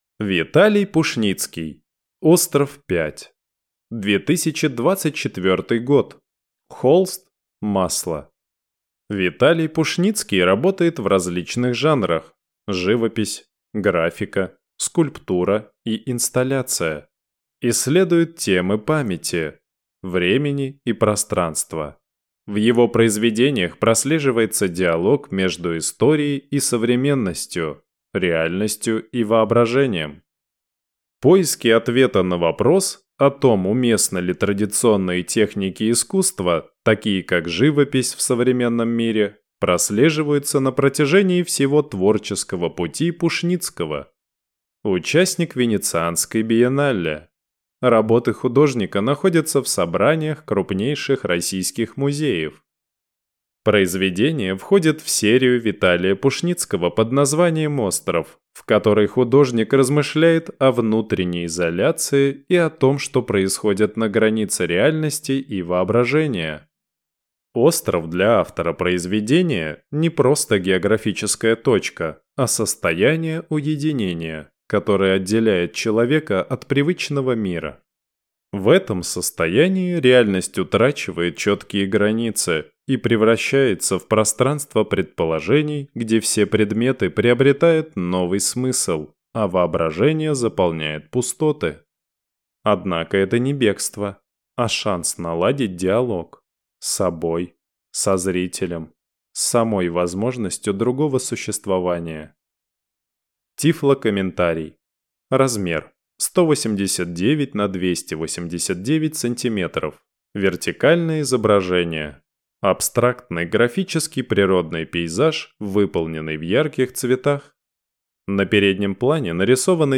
Тифлокомментарий к картине Виталия Пушницкого "Остров 5"